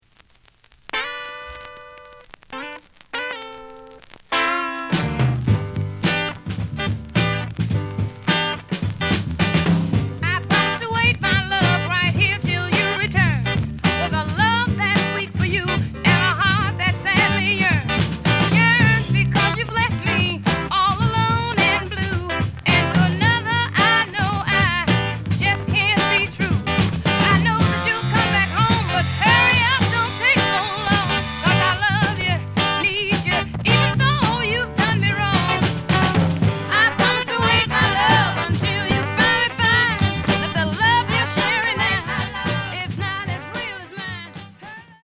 ●Genre: Soul, 60's Soul
Some noise on A side due to scratches.)